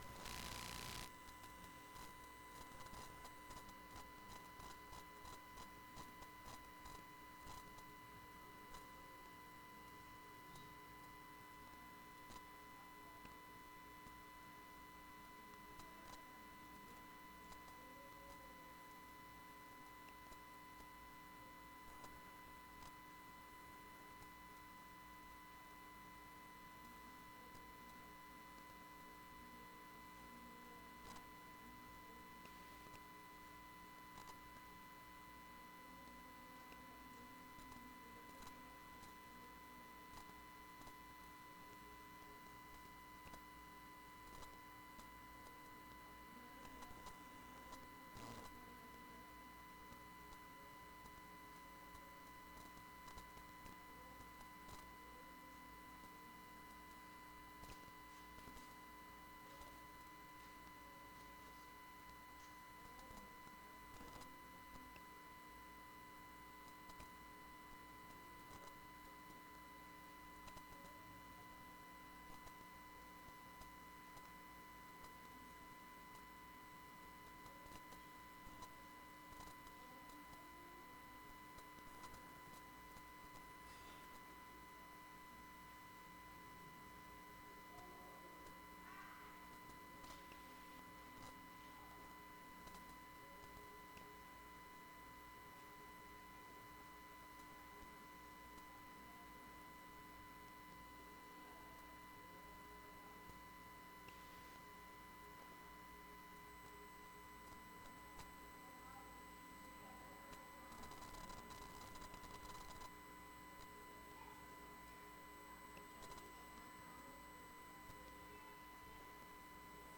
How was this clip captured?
Wednesday Service Apostolic Patterns and Methods